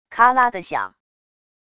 喀拉地响wav音频